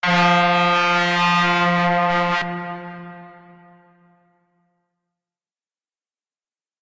Truck Horn Long Length